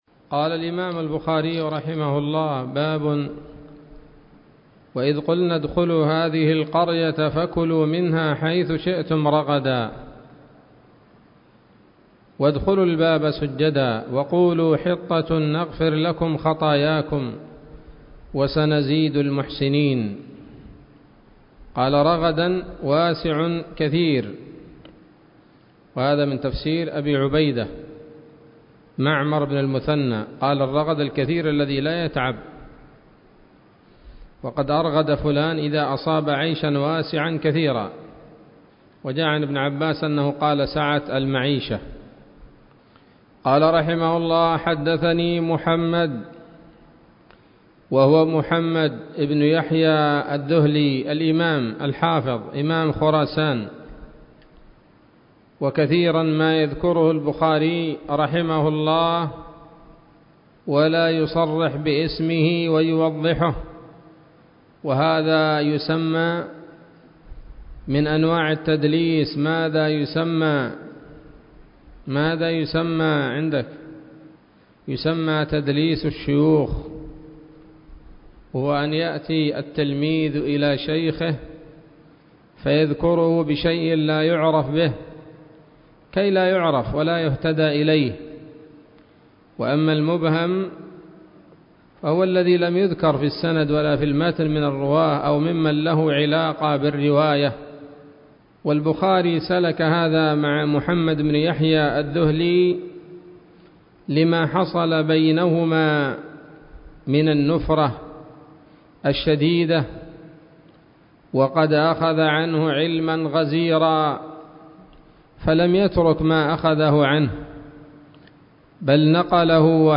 الدرس السادس من كتاب التفسير من صحيح الإمام البخاري